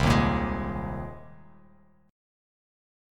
Cm7#5 chord